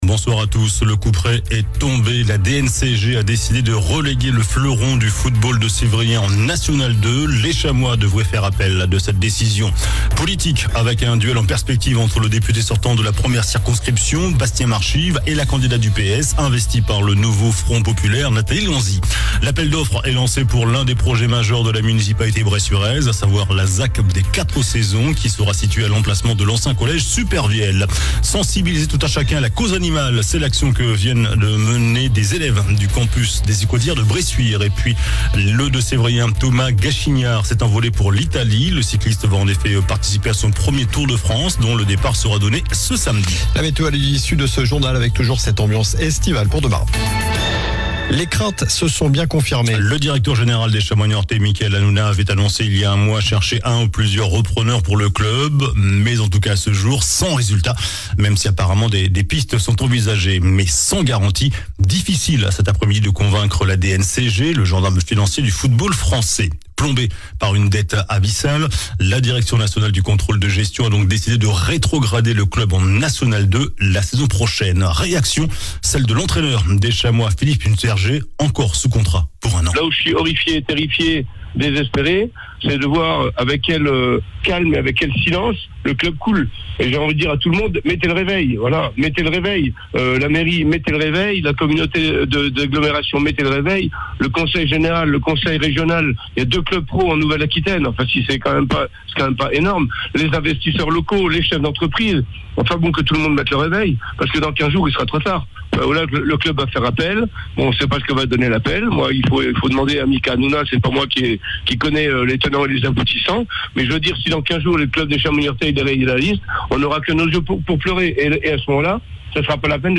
JOURNAL DU MERCREDI 26 JUIN ( SOIR )